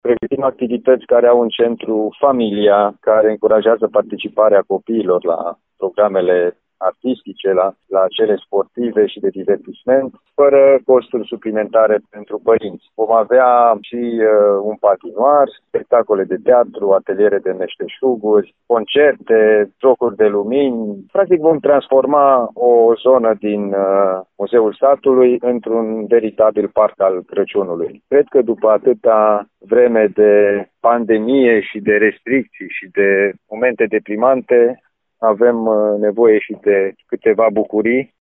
La toate aceste activități intrarea va fi gratuită, pe un teren care depășește zece hectare, spune președintele CJ Timiș, Alin Nica.